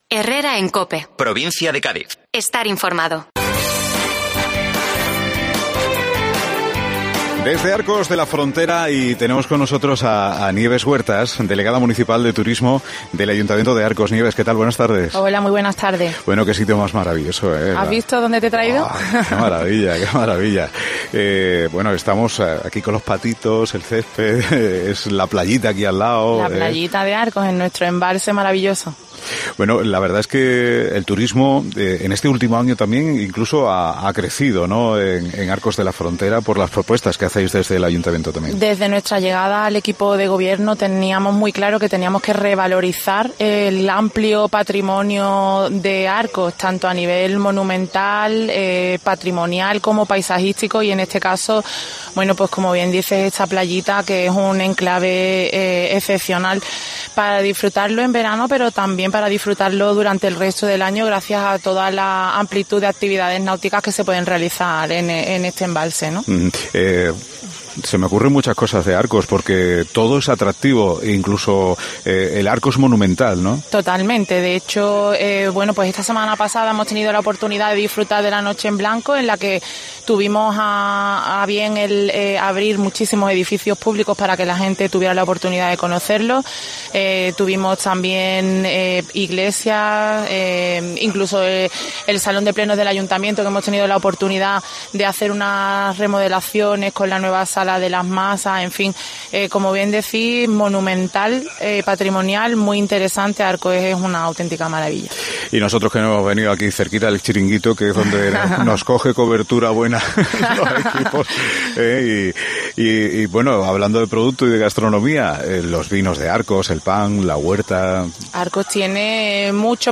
Entrevista con la presidenta de la Diputación de Cádiz, Almudena Martínez del Junco
Pero hoy nos hemos ubicado en el Club Náutico de Arcos, junto al lago en una playa interior que ofrece servicio de socorrismo y cuenta con un restaurante con terraza exterior con vistas al lago.